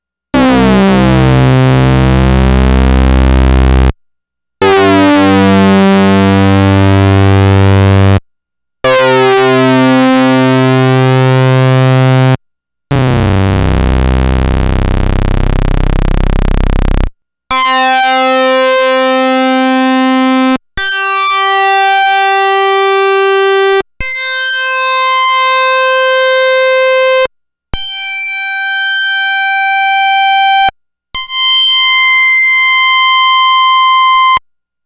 Aucune compression n'a été appliquée, c'est ce qui sort direct.
P08SawSync.wav